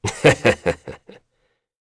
Lusikiel-Vox_Happy1.wav